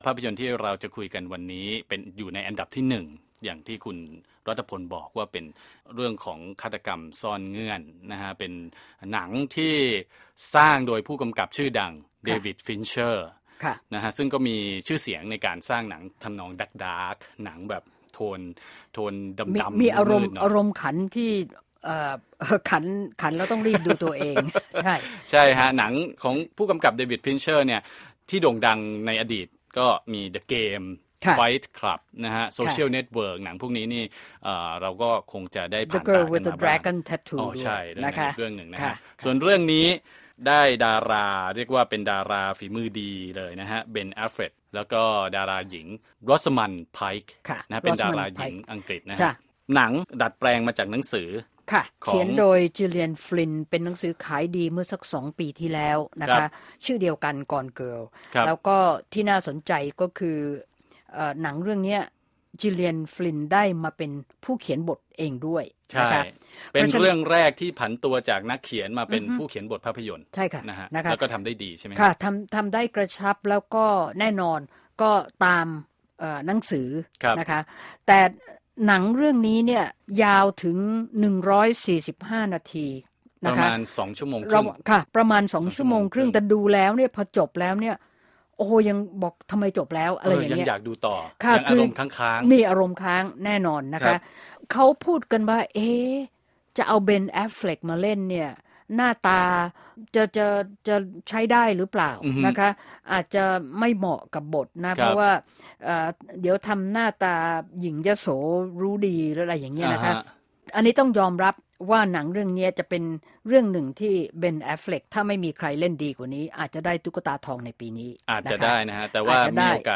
Movie Review: Gone Girl